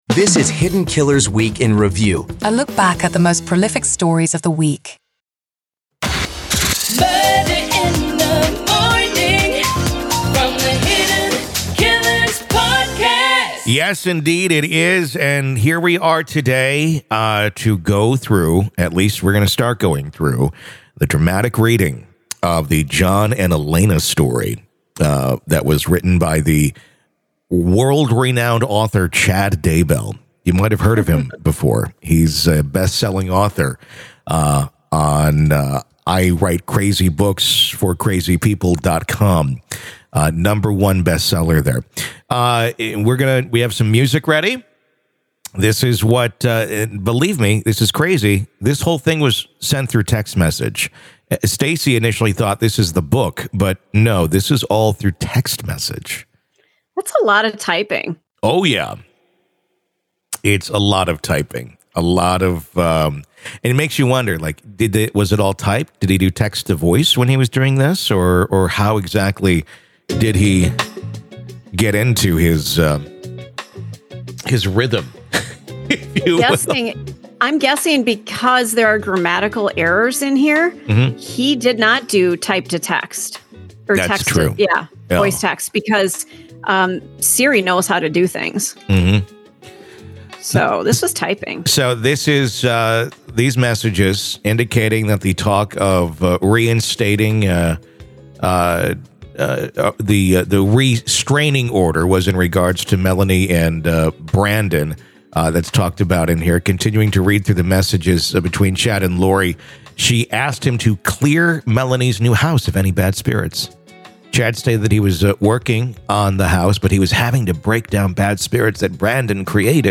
DAYBELL READING 1 CHAPTER 1-WEEK IN REVIEW